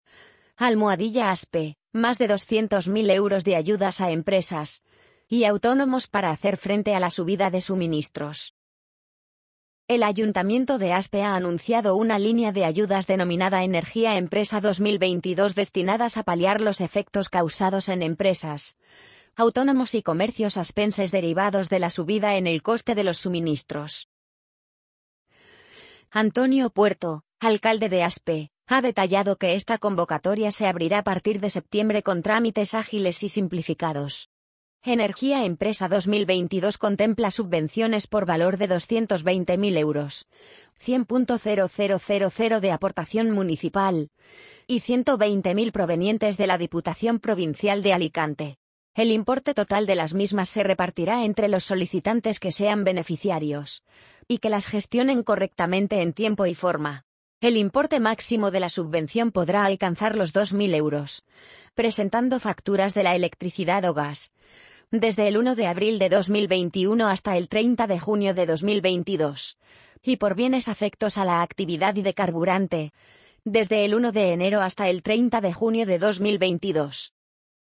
amazon_polly_59346.mp3